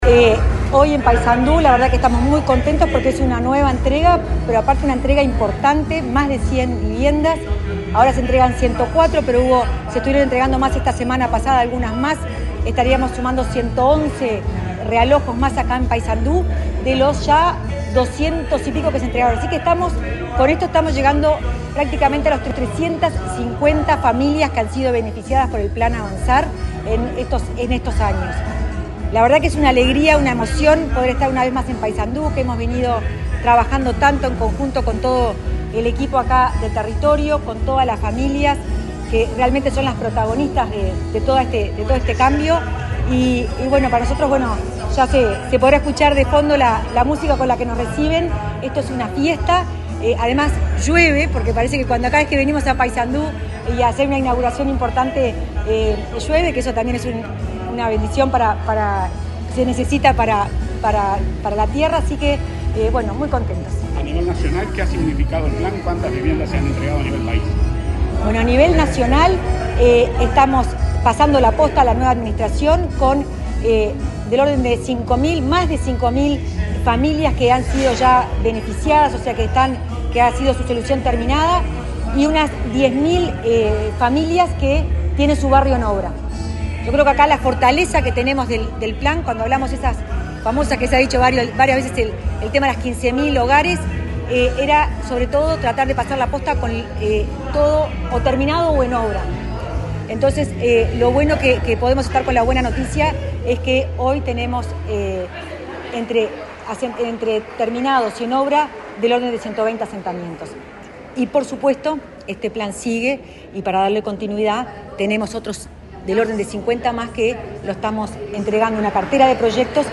Declaraciones de la directora de Integración Social y Urbana del MVOT, Florencia Arbeleche 26/02/2025 Compartir Facebook X Copiar enlace WhatsApp LinkedIn La directora de Integración Social y Urbana del Ministerio de Vivienda y Ordenamiento Territorial (MVOT), Florencia Arbeleche, dialogó con la prensa, durante el acto de entrega de viviendas en Paysandú, en el marco del plan Avanzar.